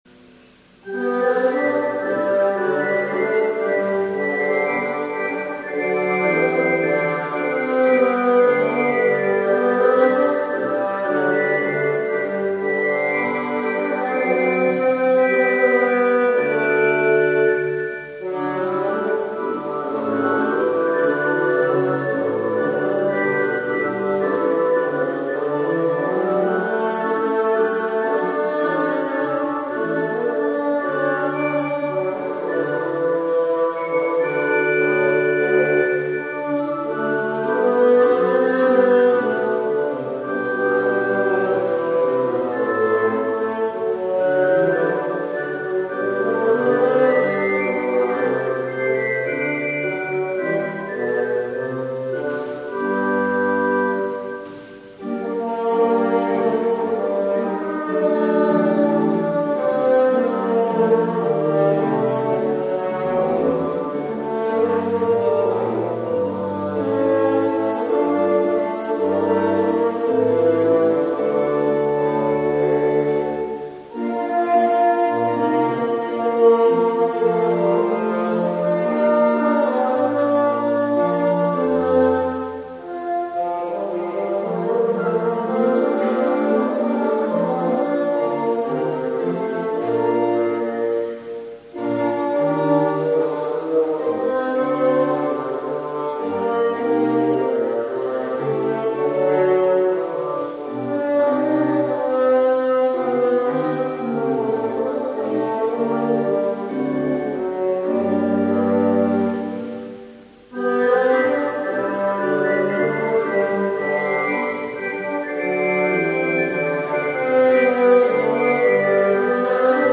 Pfingst-Gottesdienst vom 23.05.2021
begleitet mit Fagott und Orgel
als Podcast zum nachhören Gekürzte Fassung